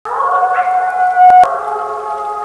le ciel, l'animal lance un hurlement d'environ
07wolves.wav